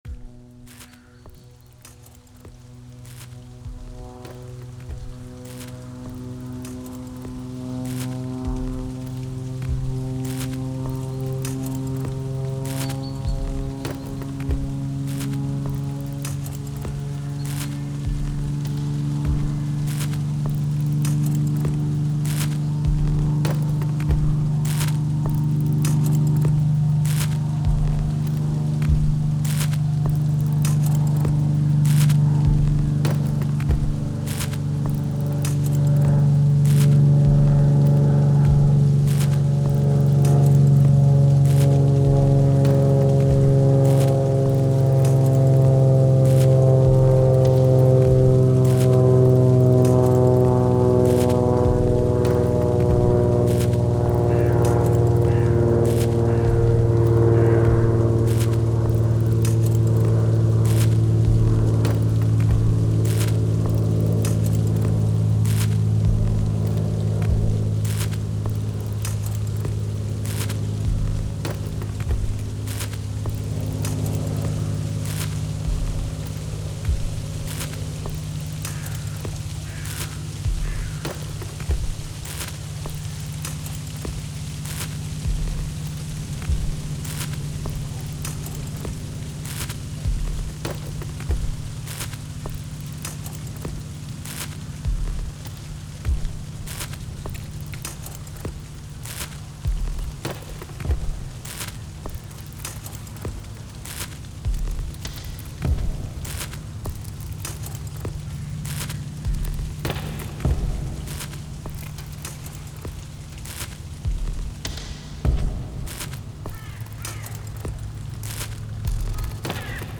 Das ist eine Klanglandschaft, deren zugrundeliegenden Aufnahmen am 1. Advent und der Woche vor dem 2. Advent entstanden sind. Die natur wird leise, die Märkte laut. Die ersten Aufnahmen entstanden in Tiefenau an 2 Teichen. Die erste Session ist dominiert von einem Fliegergeräusch und Krähen – die zweite Aufnahme macht eine Gruppe von Singschwänen hörbar.
Am schwierigsten waren die letzten Aufnahmen vom Weihnachtsmarkt in Pirna.